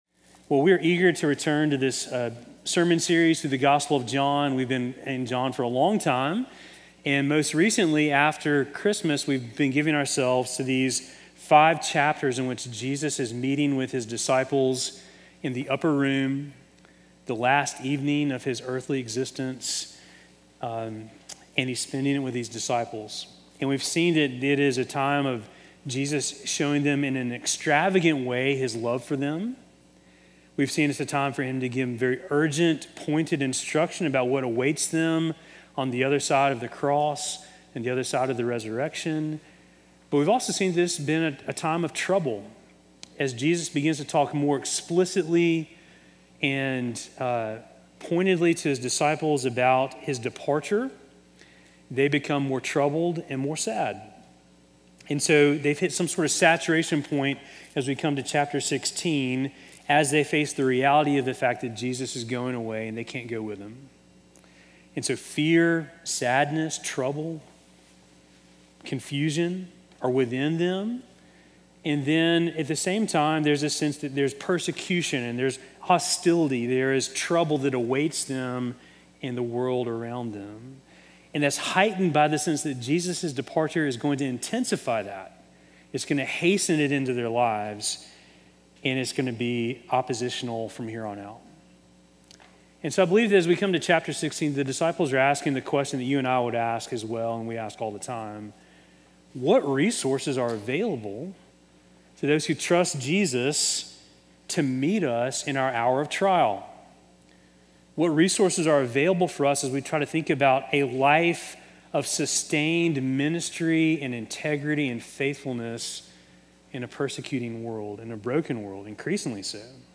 Sermon from March 1